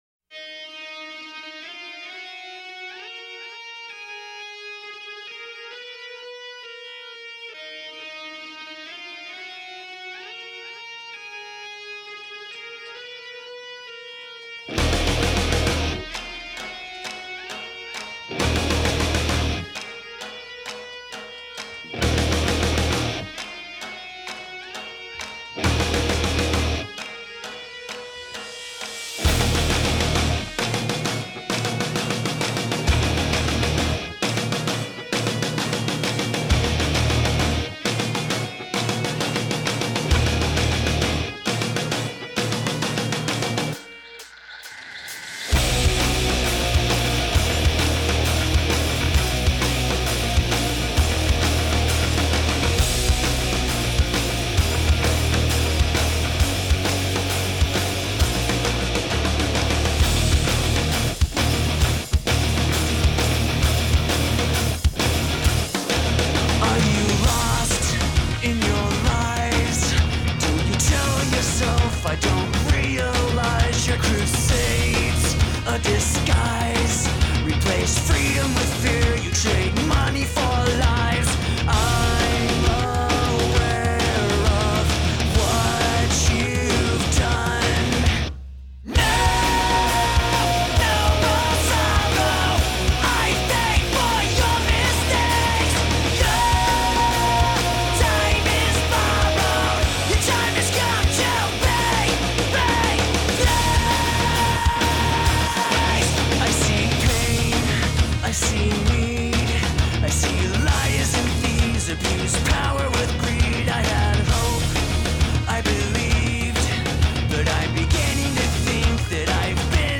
Категория: Рок/Rock